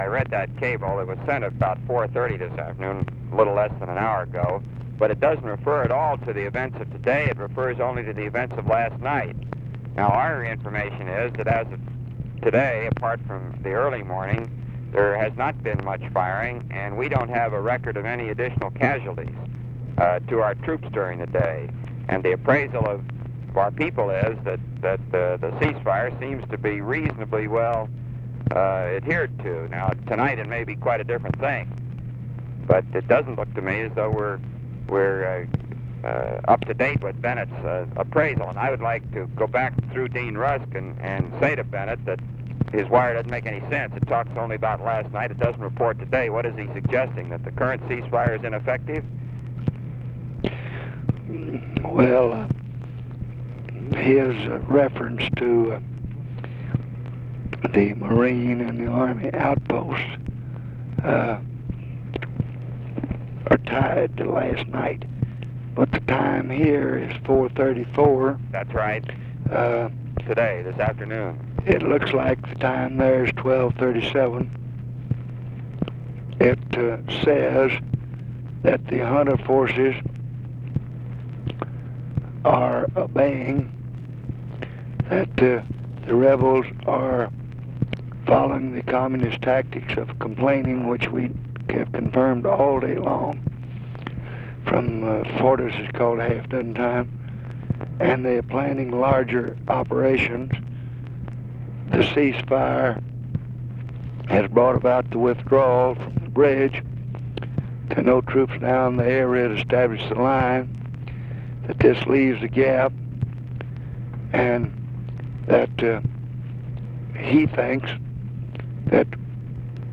Conversation with ROBERT MCNAMARA, May 1, 1965
Secret White House Tapes